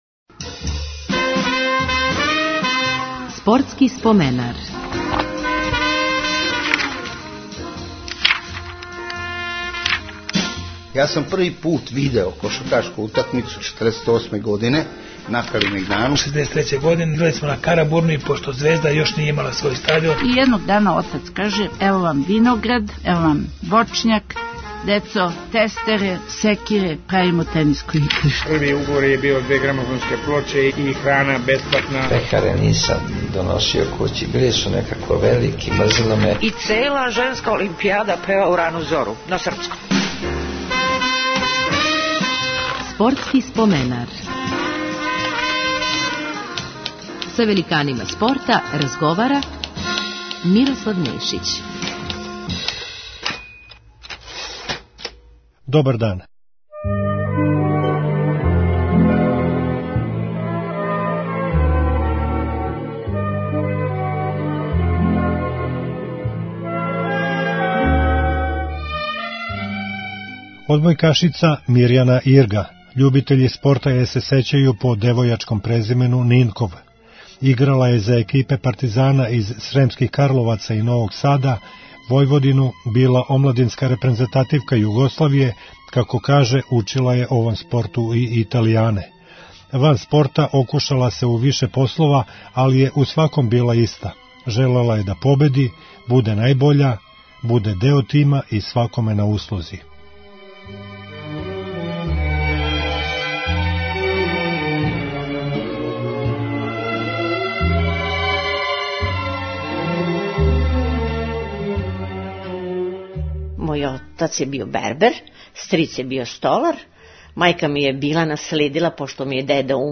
Пре неколико година био је гост "Спортског споменара", а за ову прилику издвојили смо тонски запис о утакмици ОКК Београд-Реал Мадрид , која је необично дуго трајала, тријумфу репрезентације Југославије на незваничном Првенству света у Чилеу 1966. и освајању сребрне олимпијске медаље у Мексику две године касније.